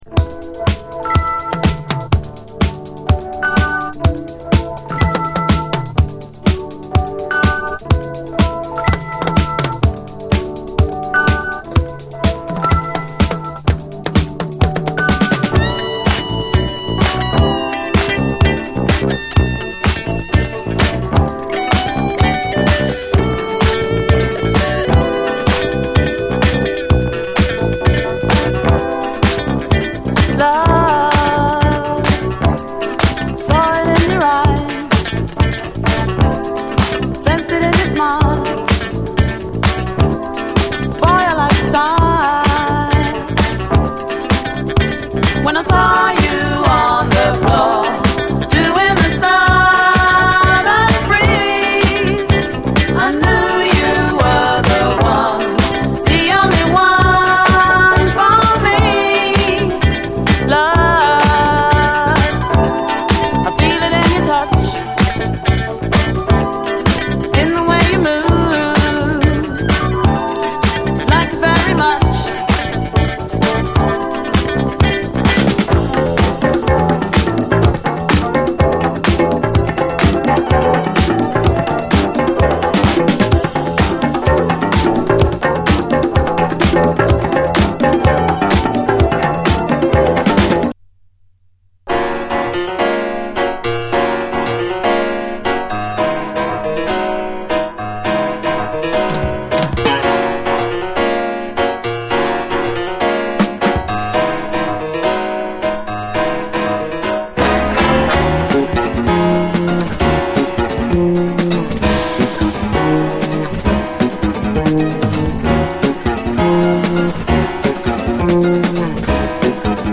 SOUL, 70's～ SOUL, DISCO